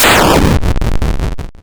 factory_destroyed.wav